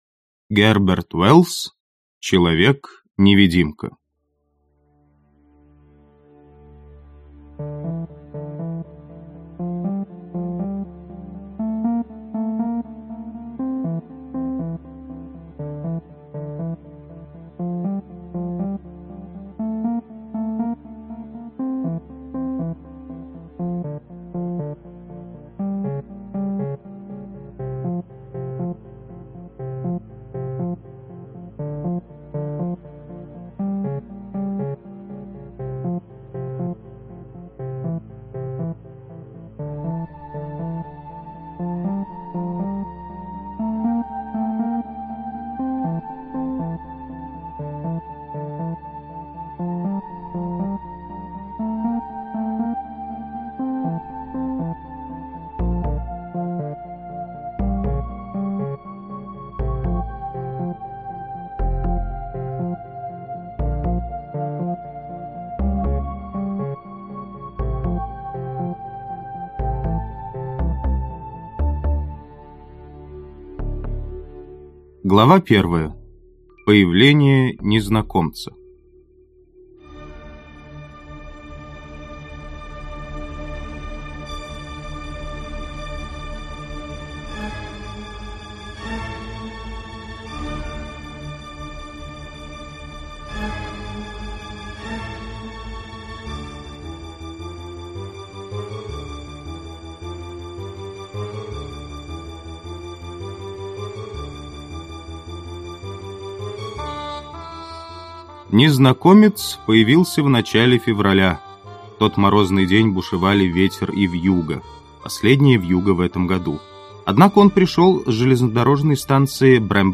Аудиокнига Человек-невидимка | Библиотека аудиокниг